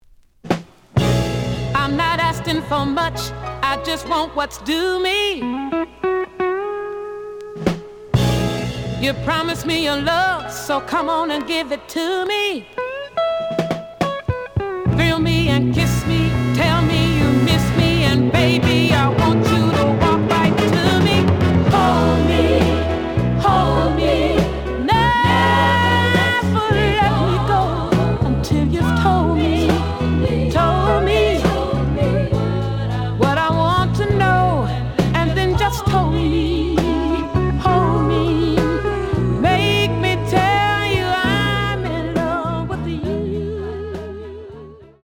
The audio sample is recorded from the actual item.
●Genre: Soul, 70's Soul